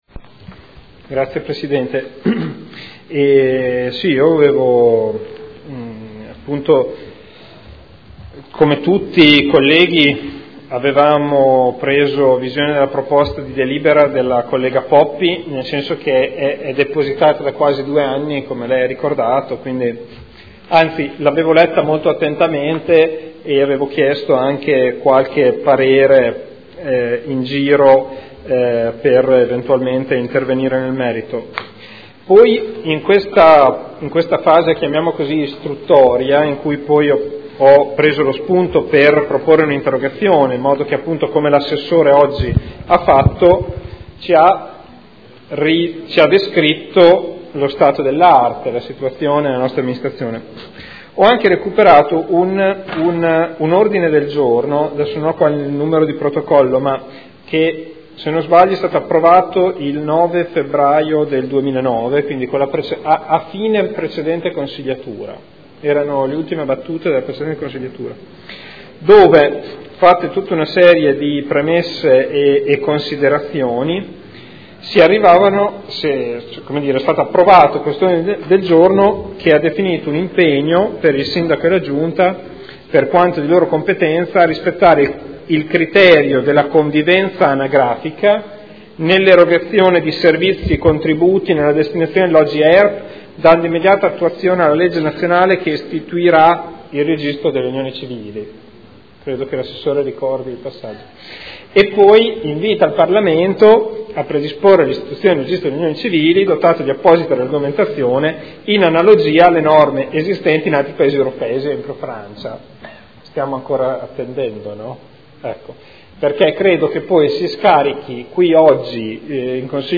Federico Ricci — Sito Audio Consiglio Comunale
Seduta del 3 aprile. Interrogazione del consigliere Ricci (SEL) avente per oggetto: “Unioni civili” L’interrogazione verrà trattata unitamente alla proposta di deliberazione della consigliera Poppi.